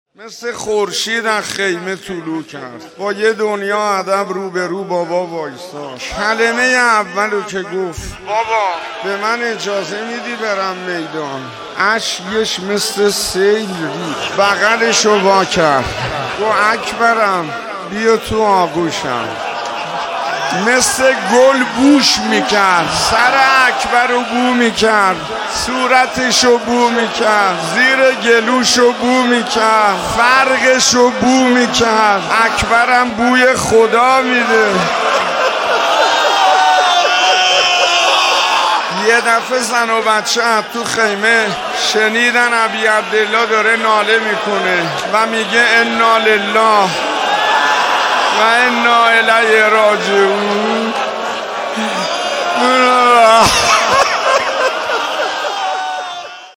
سخنرانی استاد شیخ حسین انصاریان محرم الحرام 1441 حسینیه هدایت تهران